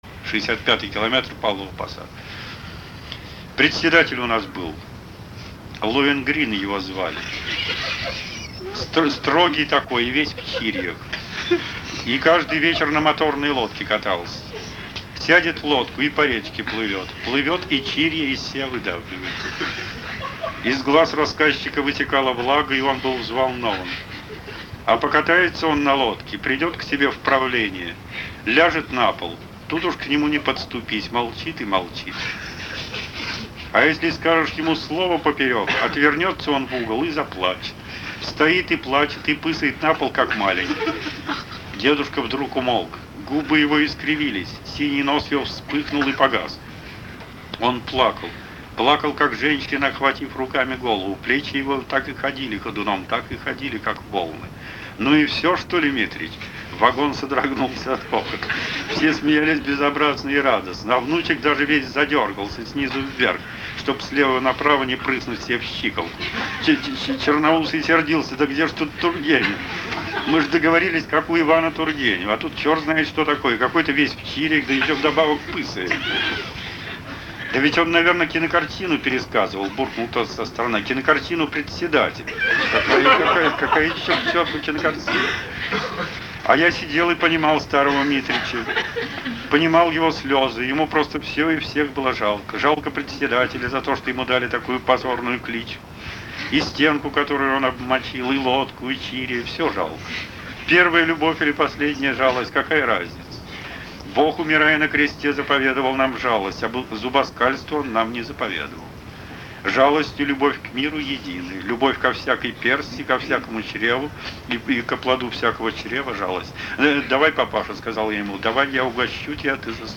8. «Венедикт Ерофеев.”Москва – Петушки” – 65-й километр – Павлово-Посад (читает автор)» /
Erofeev.Moskva-Petushki-65-j-kilometr-Pavlovo-Posad-chitaet-avtor-stih-club-ru.mp3